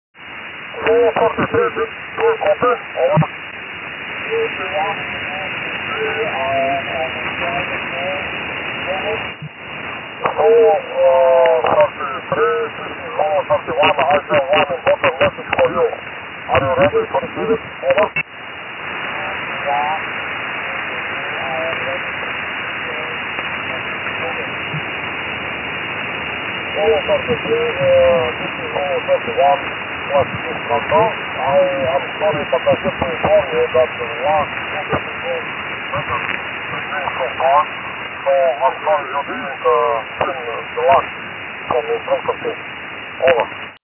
аудио-сигнал после сдвига 1100 Гц на ноль
1100hz_shift.mp3